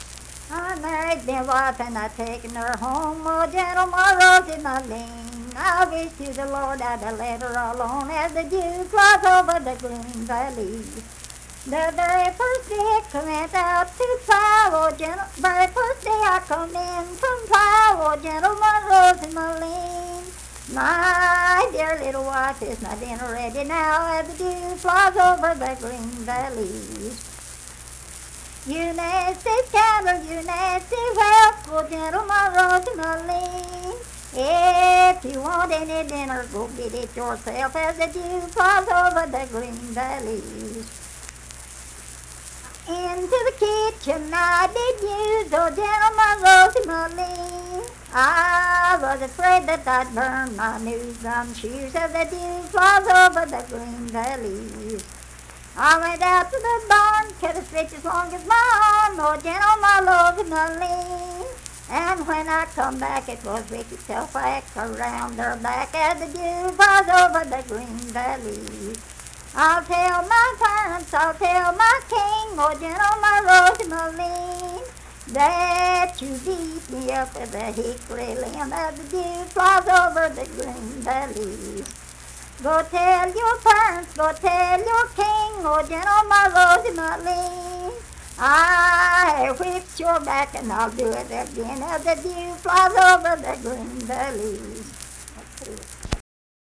Unaccompanied vocal music performance
Verse-refrain 7(4w/R).
Voice (sung)